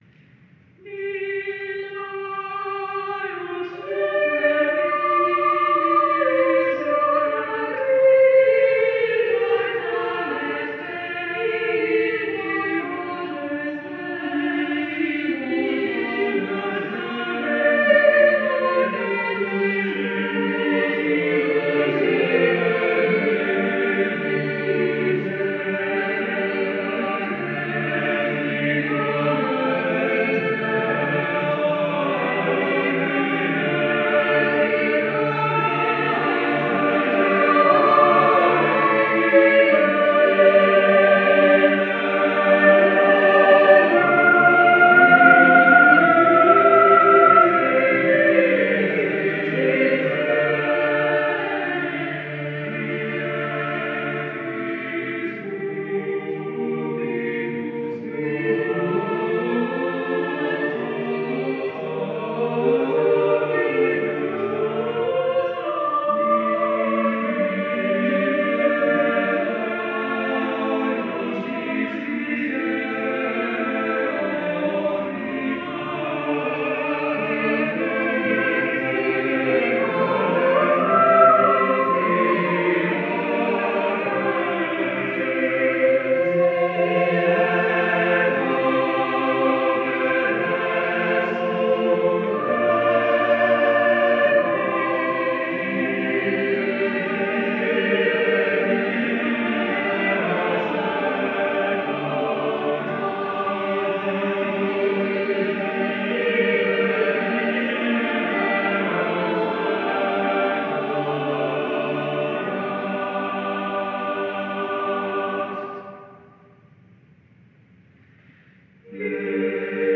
This laudatory motet comes at the end of the motet section of the Part-books, and is cited as primary evidence of his argument.